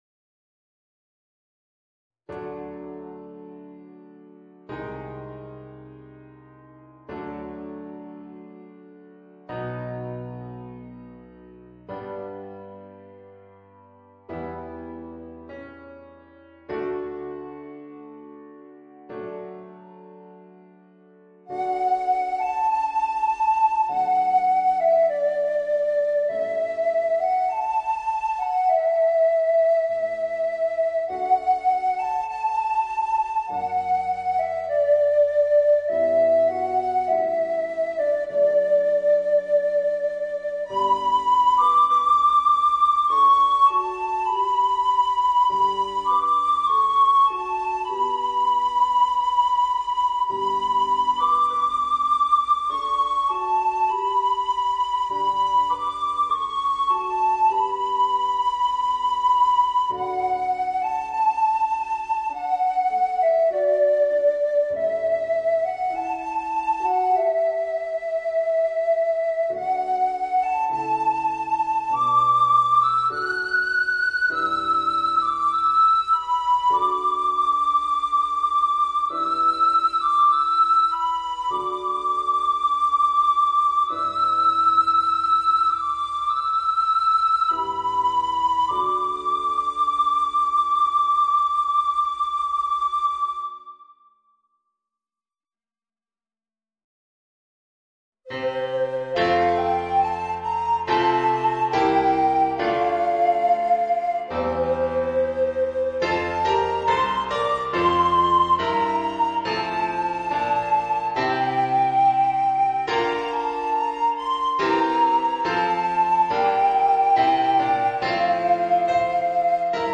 Soprano Recorder and Organ